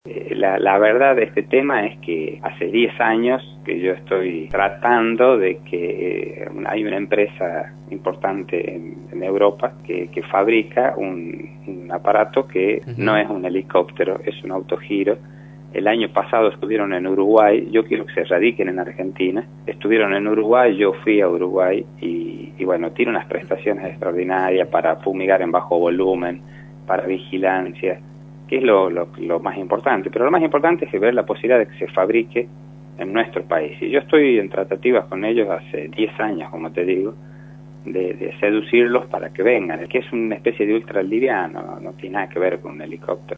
Gustavo Minuzzi, intendente de Arauco, por Radio La Red